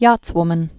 (yätswmən)